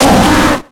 Cri de Cochignon dans Pokémon X et Y.